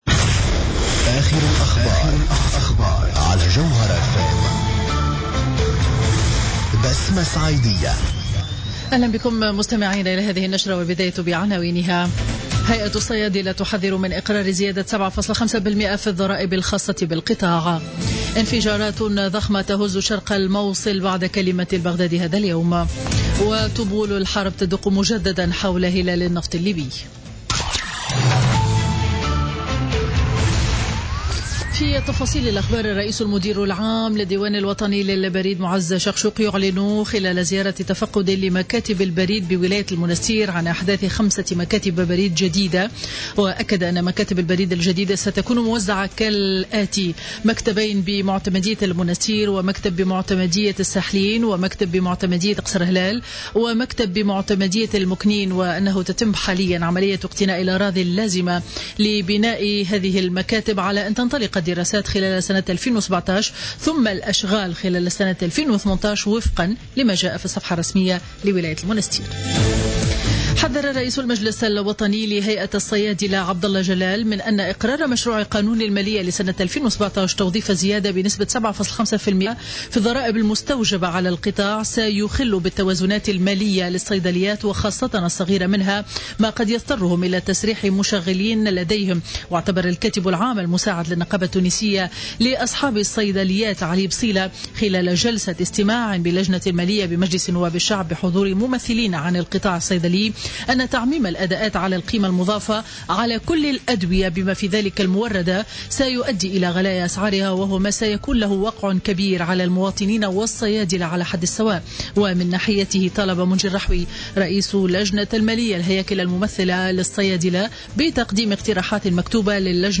نشرة أخبار منتصف النهار ليوم الخميس 3 نوفمبر 2016